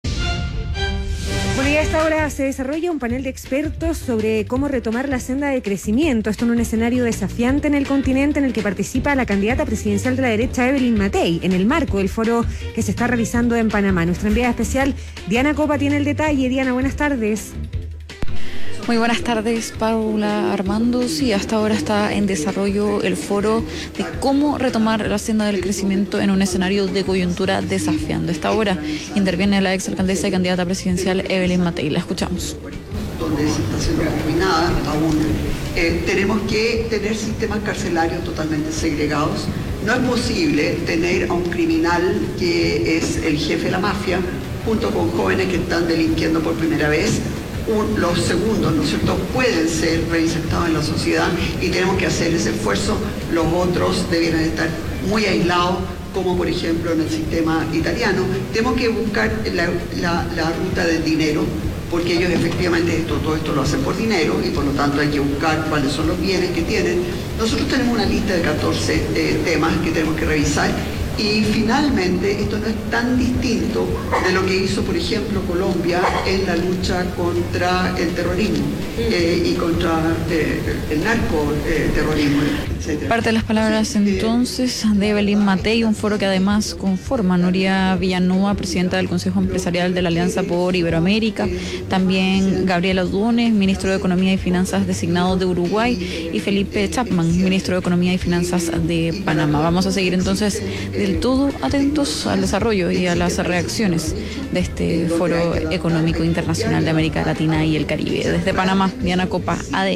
Intervención de Evelyn Matthei en Foro Económico Internacional en Panamá
En su intervención en un panel dedicado al tema, Matthei propuso reformas en el sistema carcelario, destacando la importancia de la segregación entre los distintos tipos de delincuentes.